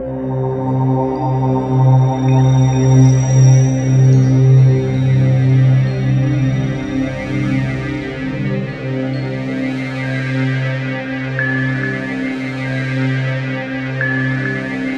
TUBULARC2.-L.wav